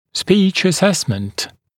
[spiːʧ ə’sesmənt][спи:ч э’сэстмэнт]оценка речевых навыков, оценка речи